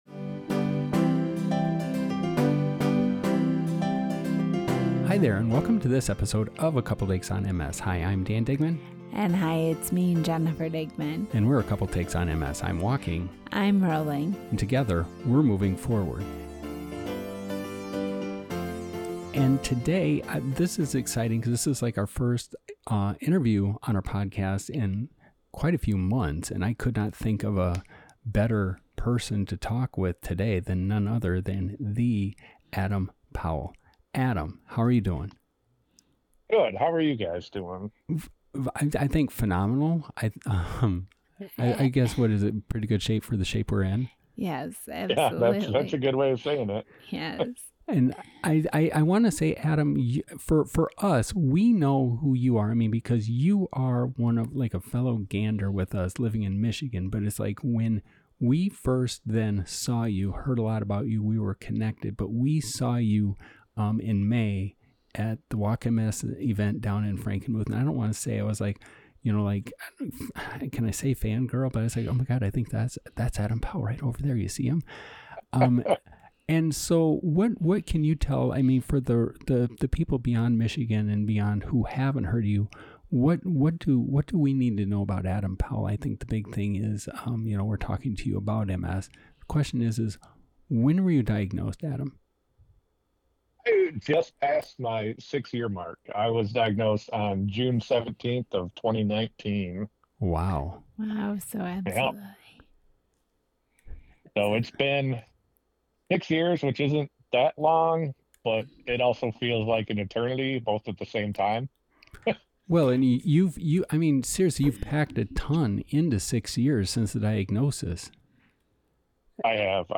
Join us for this engaging and insightful conversation